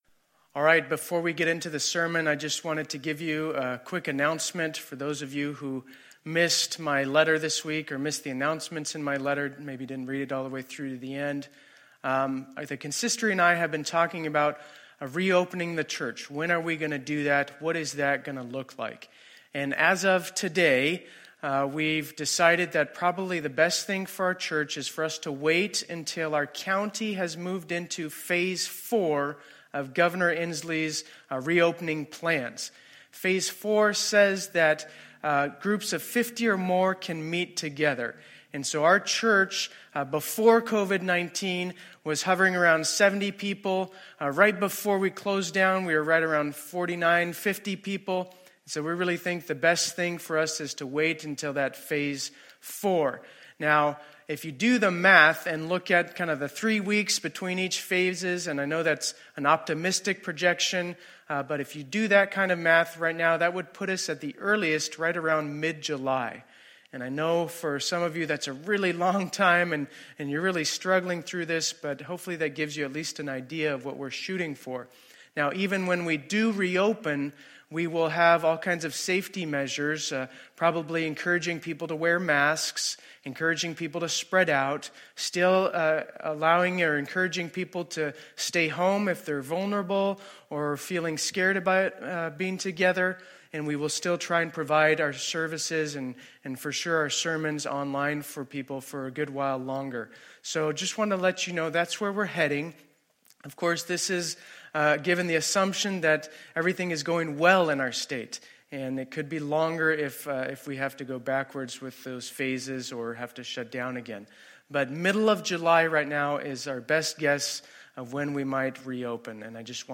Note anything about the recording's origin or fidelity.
2020-05-24 Sunday Service